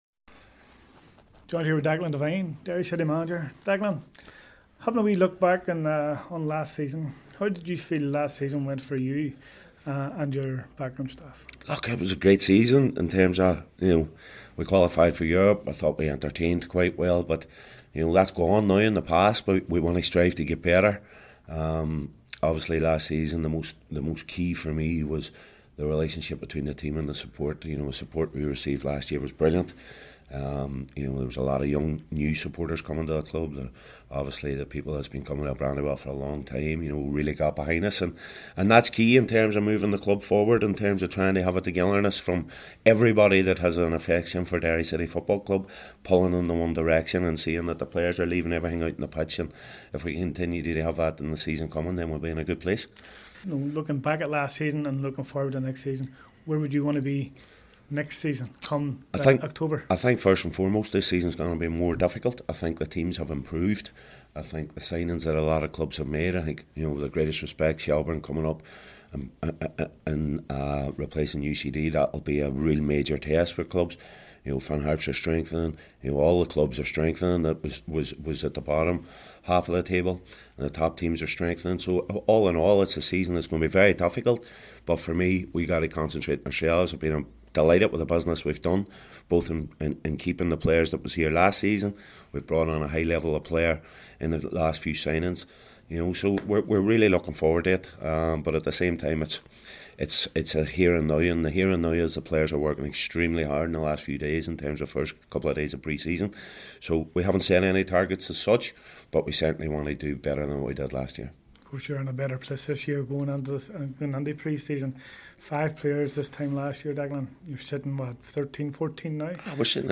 at the clubs training facility